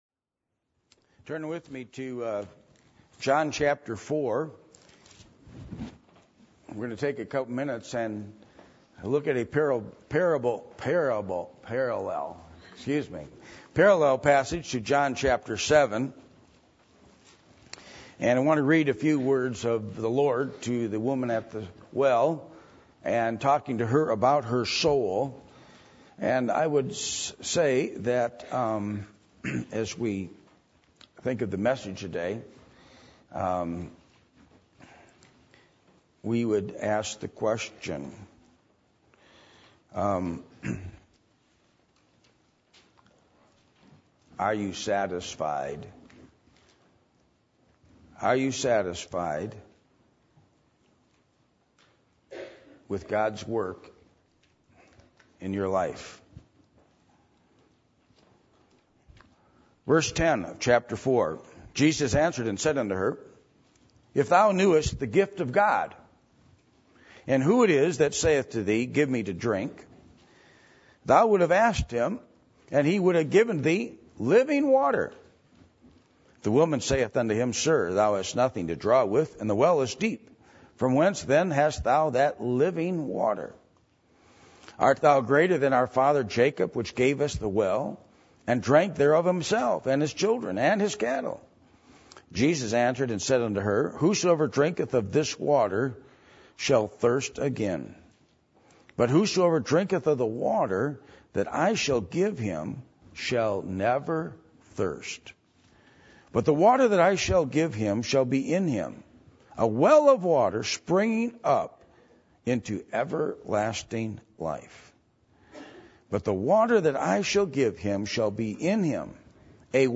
John 7:37-39 Service Type: Sunday Morning %todo_render% « Why You Should Come To Prayer Meeting How Are You Going To Finish Your Life?